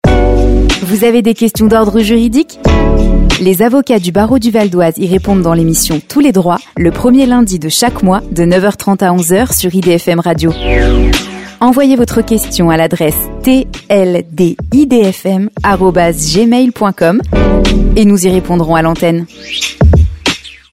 Assured
Dynamic
Engaging